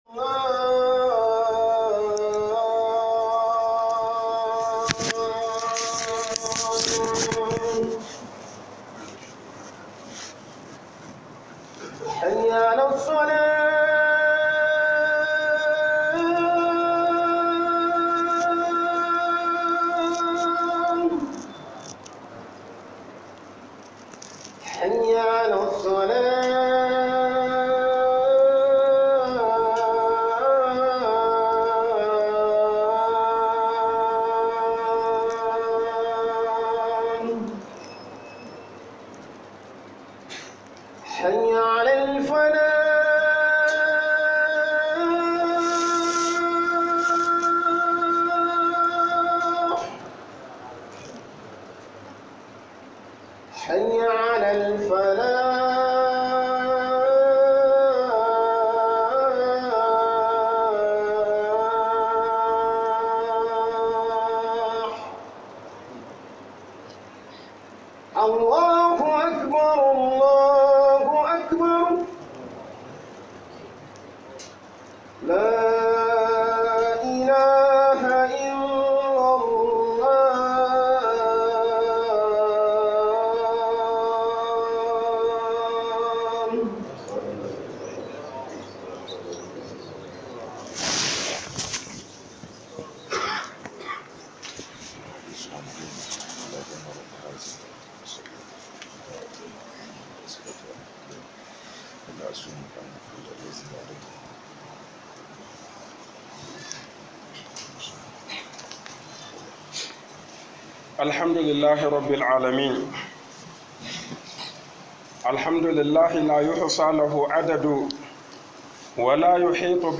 خطبة اليوم